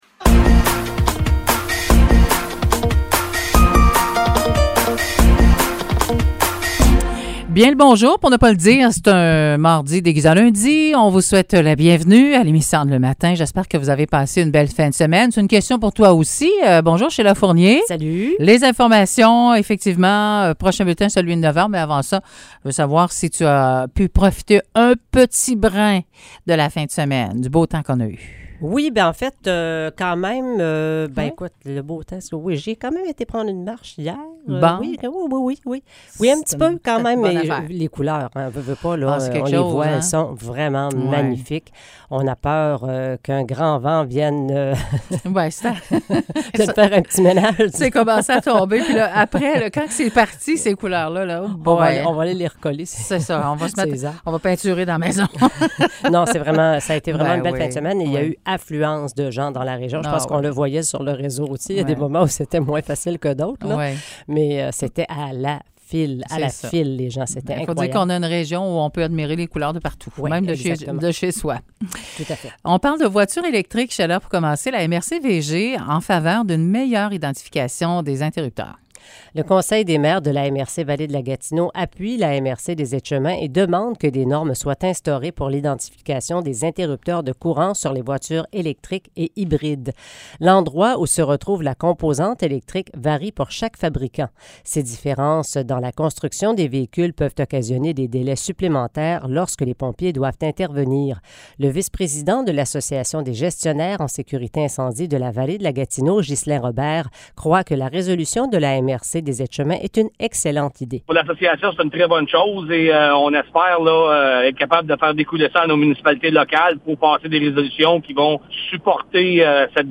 Nouvelles locales - 11 octobre 2022 - 9 h